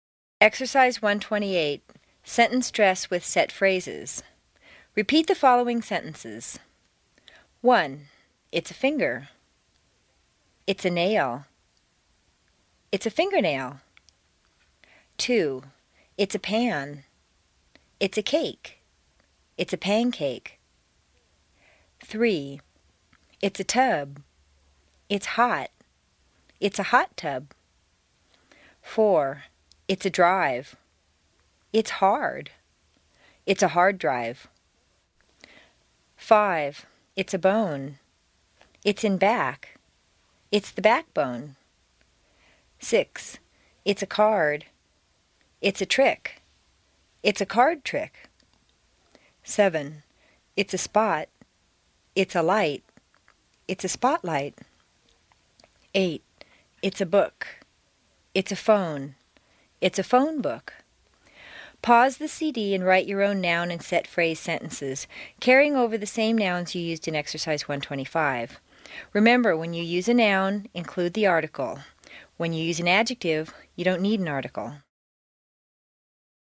美语口语发音训练 第一册41_恒星英语